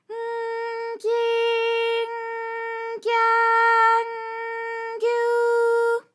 ALYS-DB-001-JPN - First Japanese UTAU vocal library of ALYS.
gy_N_gyi_N_gya_N_gyu.wav